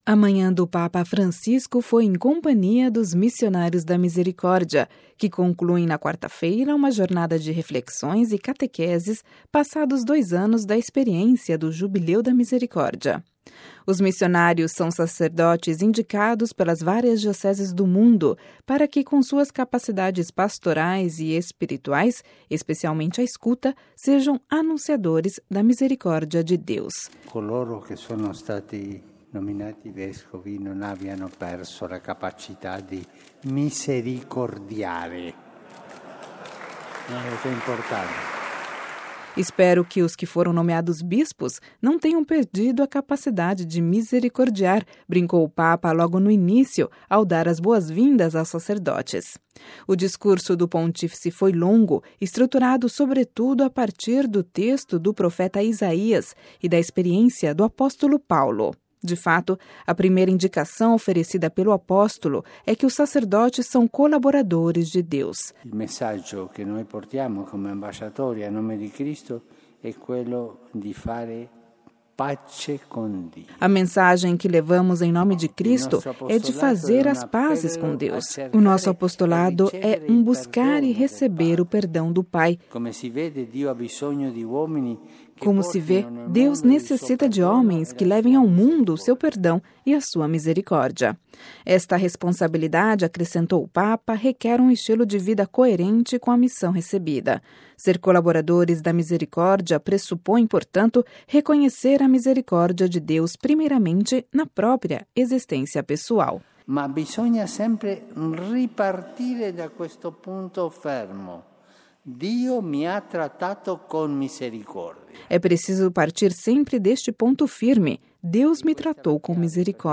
Ouça a reportagem com a voz do Papa Francisco